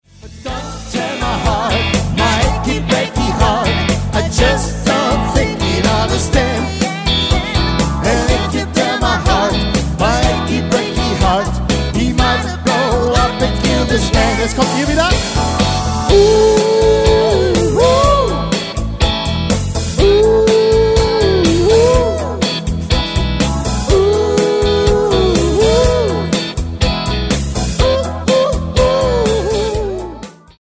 • Allround Partyband